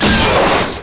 fire_missile_emp.wav